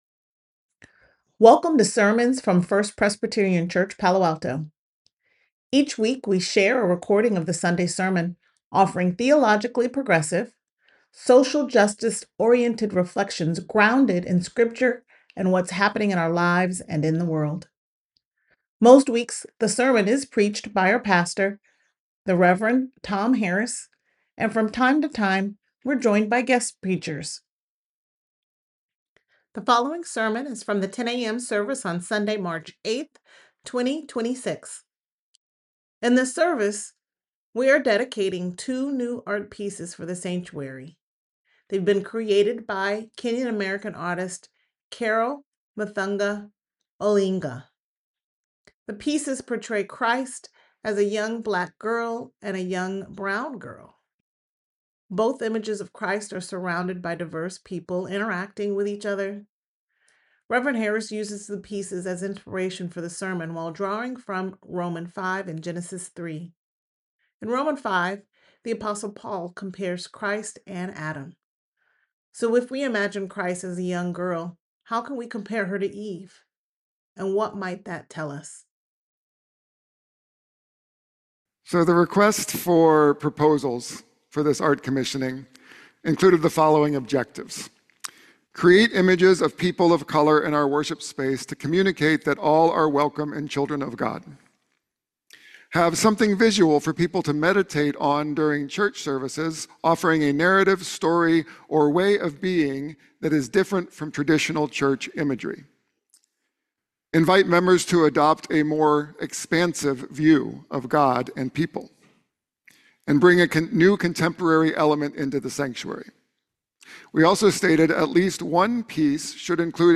The following sermon is from the 10 a.m. service on Sunday, March 8th, 2026.
Sermon3826a.mp3